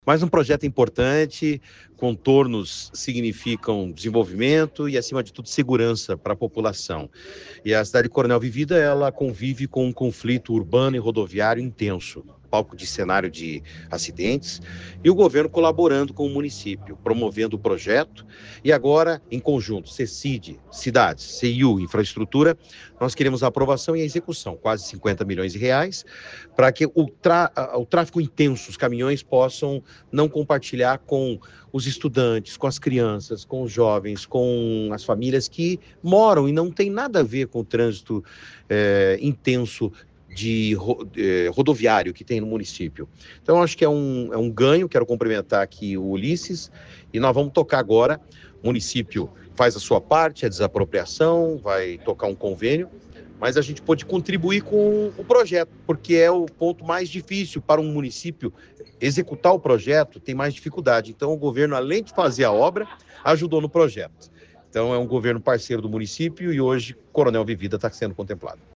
Sonora do secretário de Infraestrutura e Logística, Sandro Alex, sobre o novo contorno viário de Coronel Vivida